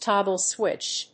アクセントtóggle swìtch